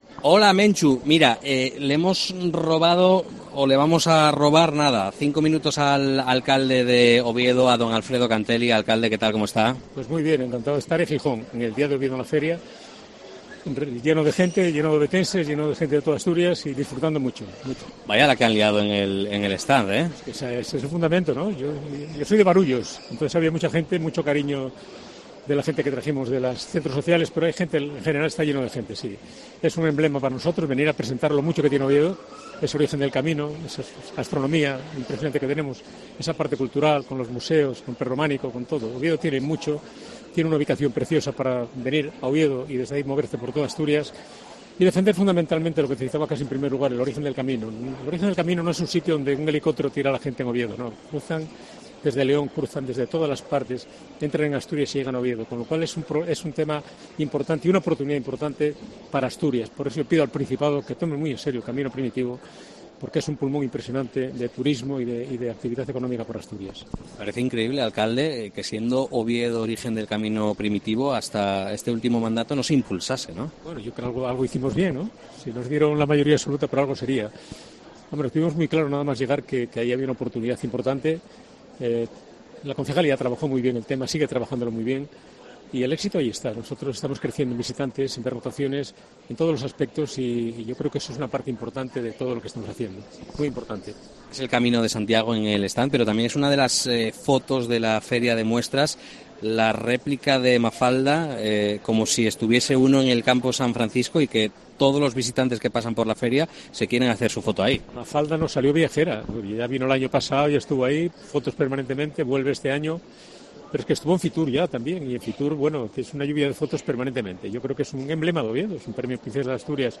Hemos hablado con el alcalde de la capital asturiana en el programa especial de COPE emitido desde el Recinto Ferial Luis Adaro con motivo de la FIDMA
FIDMA 2023: entrevista a Alfredo Canteli, alcalde de Oviedo